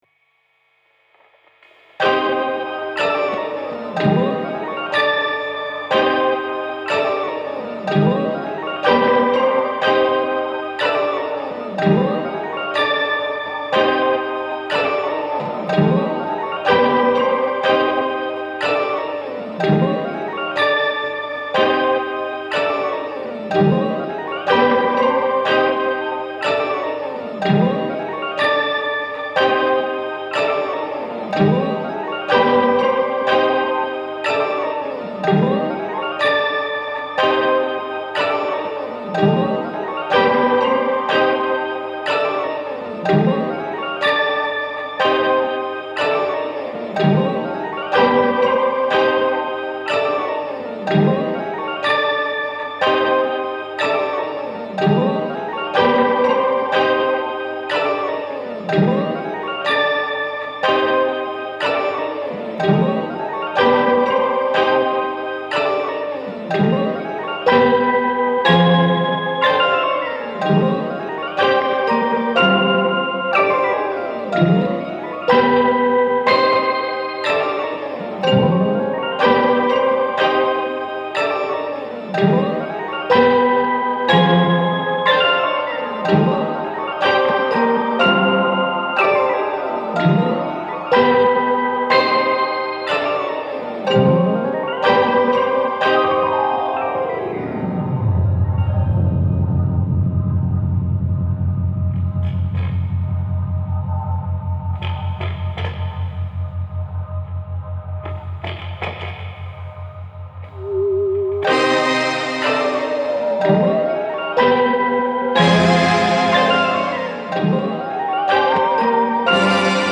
Twisted weird and obscure textures.